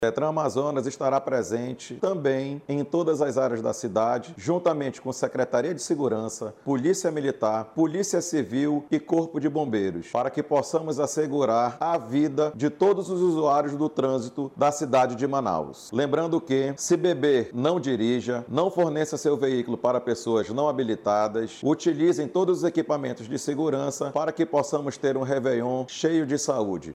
Ainda segundo Wendell Waughan, as fiscalizações serão intensificadas em todas as zonas da cidade.